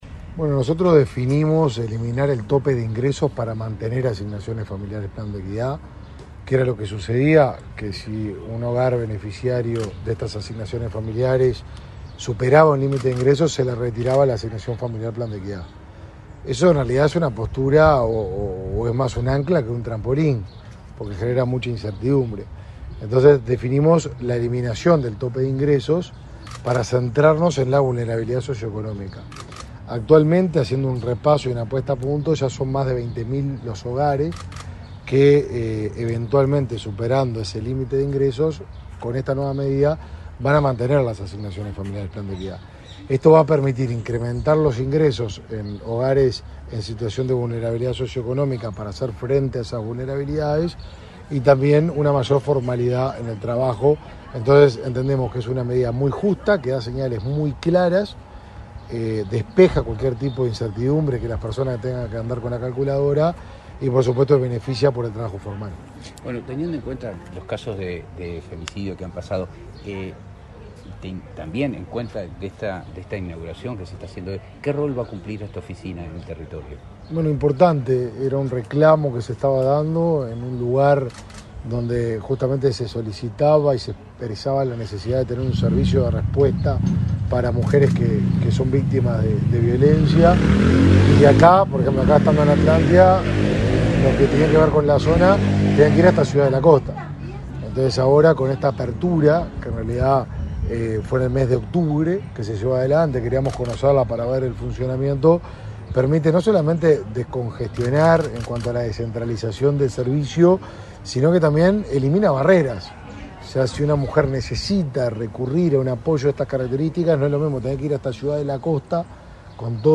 Declaraciones a la prensa del ministro de Desarrollo Social, Martín Lema
Declaraciones a la prensa del ministro de Desarrollo Social, Martín Lema 08/12/2022 Compartir Facebook X Copiar enlace WhatsApp LinkedIn Tras participar en la entrega de certificados a participantes del programa Accesos del Mides en El Pinar, Canelones, este 8 de diciembre, el ministro Lema realizó declaraciones a la prensa.